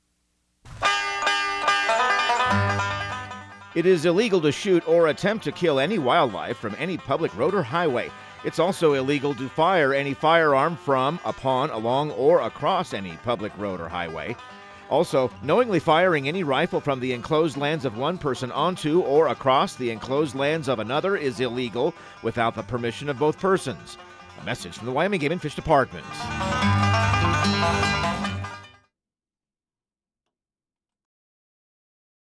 Outdoor Tip PSA